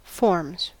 Ääntäminen
Ääntäminen US Haettu sana löytyi näillä lähdekielillä: englanti Forms on sanan form monikko.